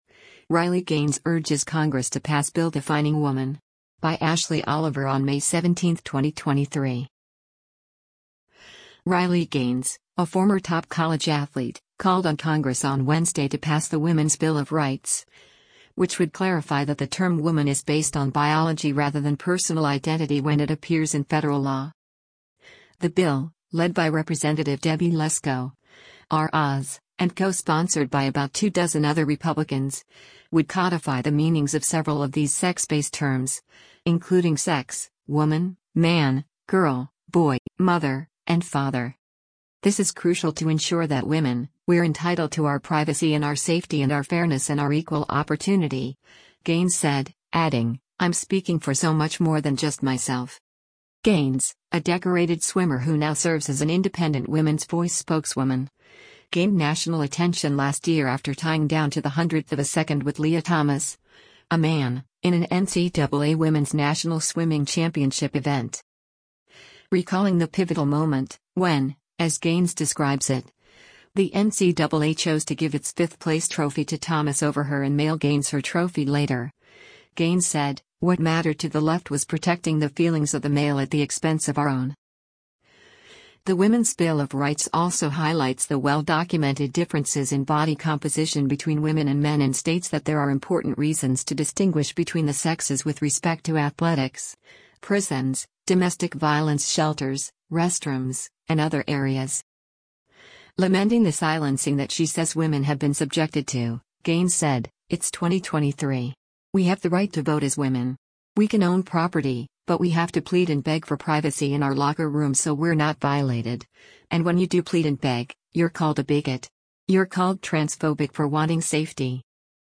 Washington, DC